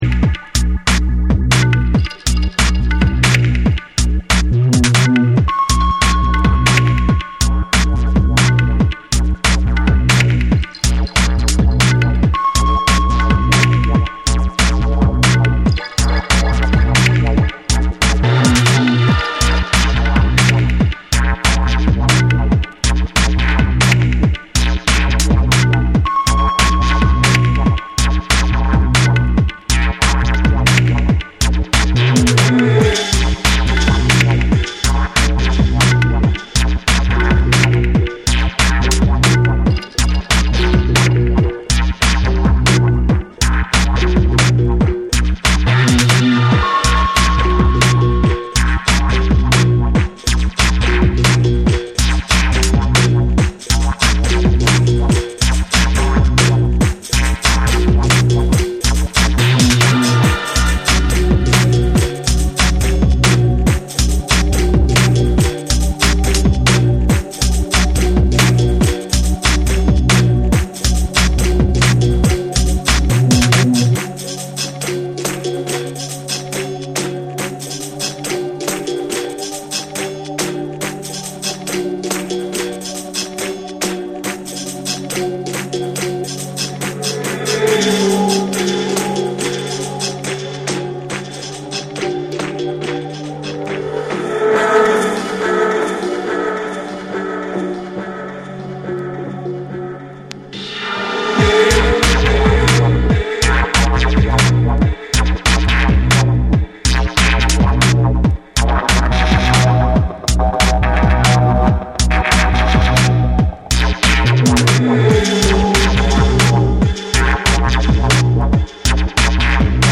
実験的な音響処理とヘヴィなグルーヴが印象的な
BREAKBEATS / DUBSTEP